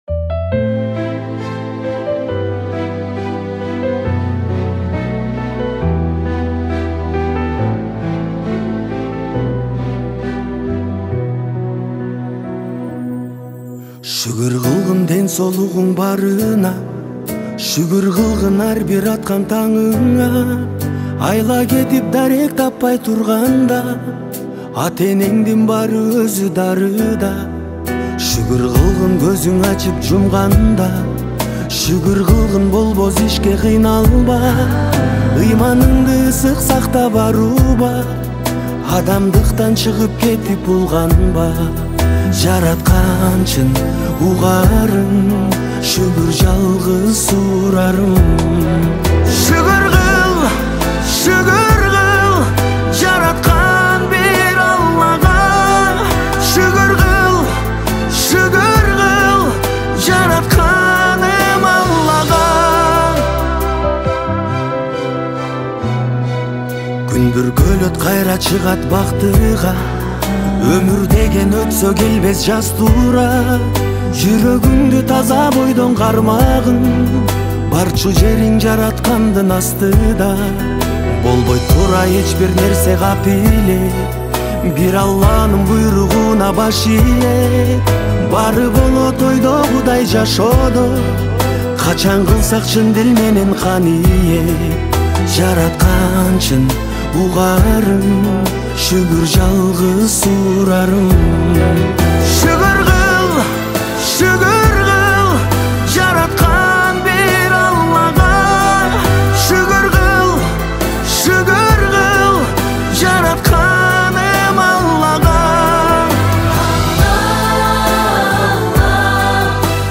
• Категория: Киргизские песни